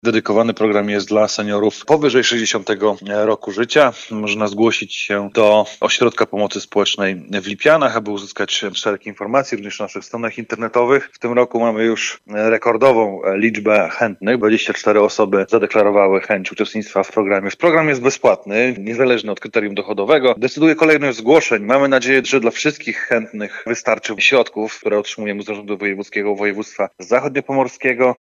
O tym, kto i w jaki sposób może to uczynić, informuje Burmistrz Bartłomiej Królikowski.